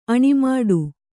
♪ aṇimāḍu